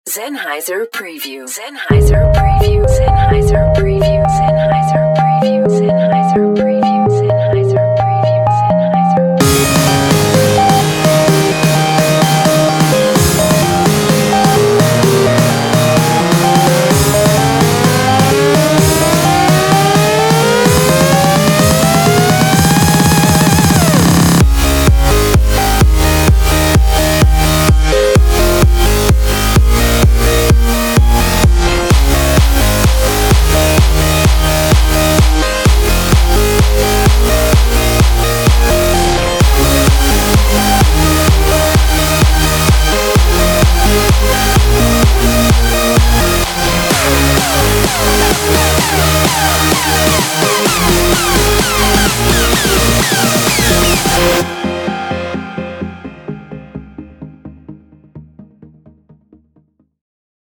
EDM
EDM, House, Trance, Electro House, Progressive House